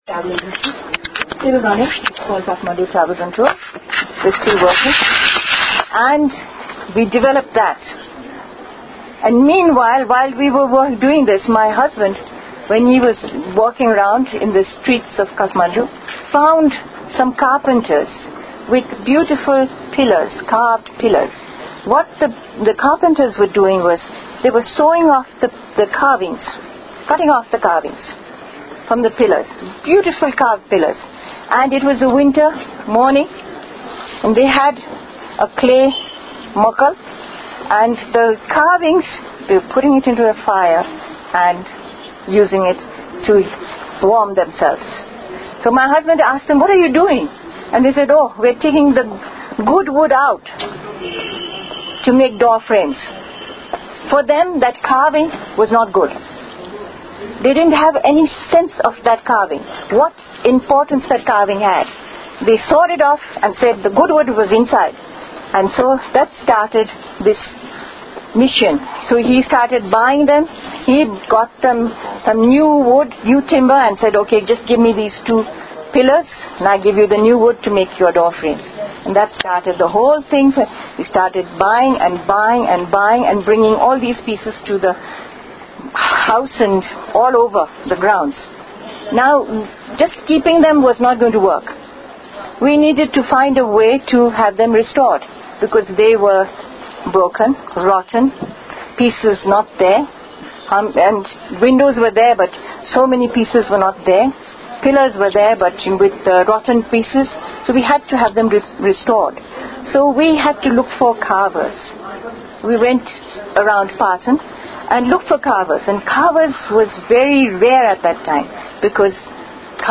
Here is a transcript of her interaction with nearly 100 young entrepreneurs.